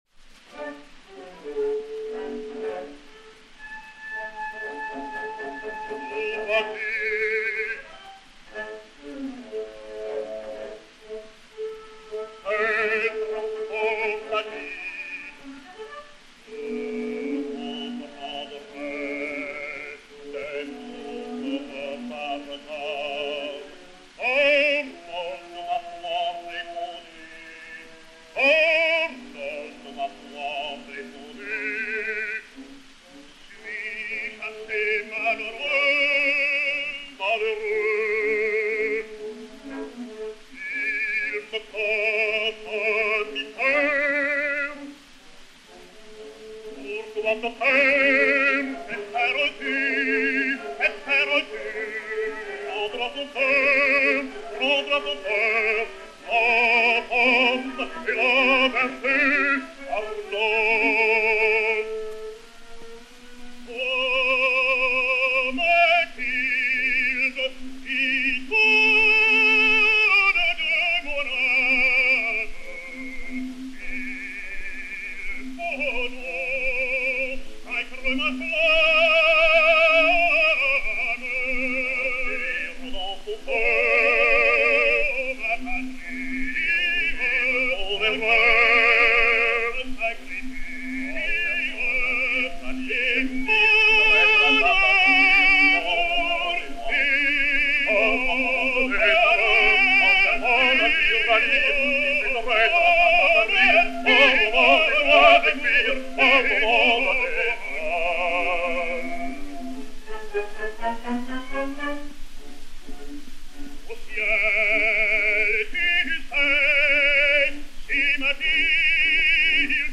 Agustarello Affre (Arnold), Henri Albers (Guillaume Tell) et Orchestre